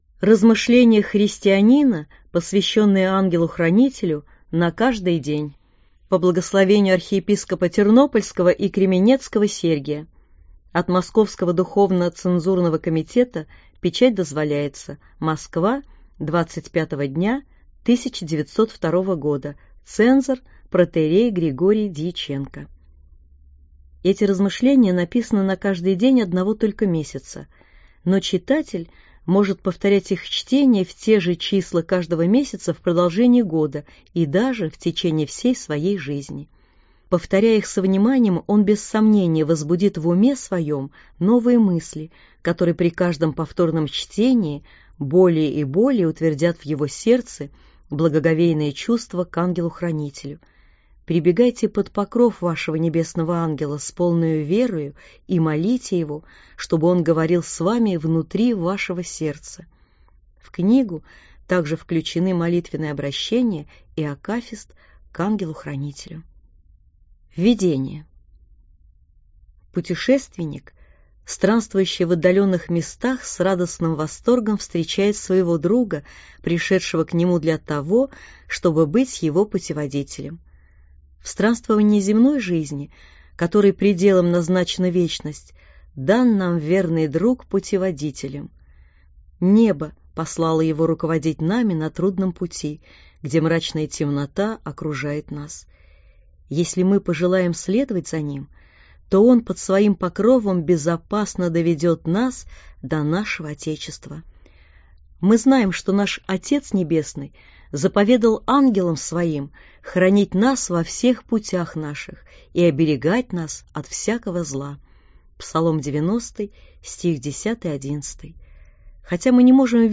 Аудиокнига Размышления христианина, посвященные Ангелу-хранителю, на каждый день | Библиотека аудиокниг